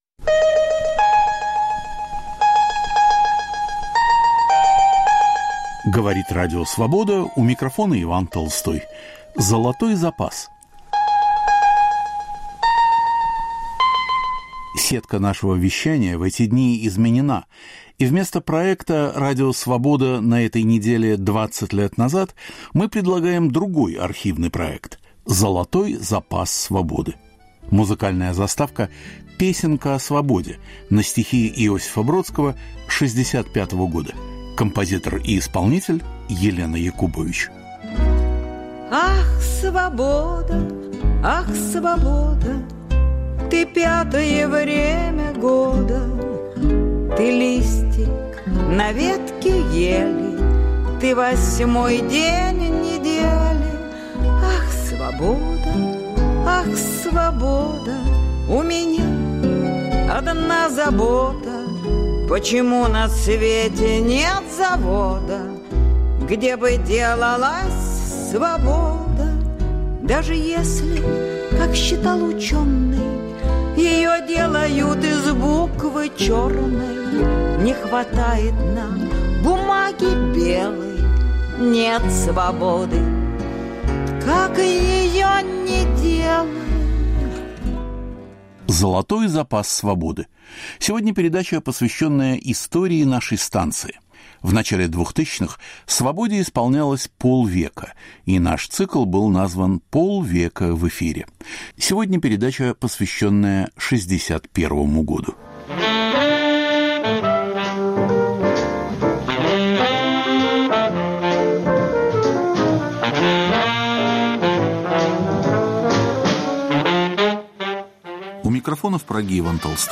Цикл передач к 50-летию Радио Свобода. Из архива: полет Юрия Гагарина, 20-летие нападения Германии на СССР, беседа Нины Берберовой о Владиславе Ходасевиче, интервью с актрисой Лилей Кедровой, антисталинский съезд КПСС, Берлинская стена, музыкальный фестиваль в Сан-Ремо.